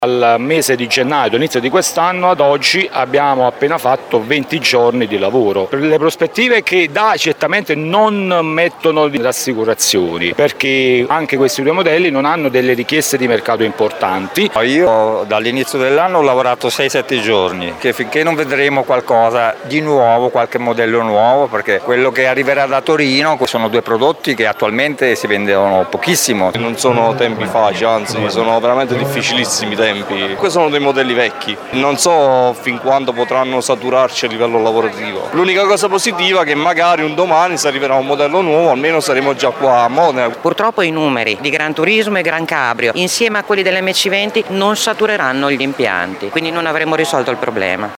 OPERAI-MASERATI.mp3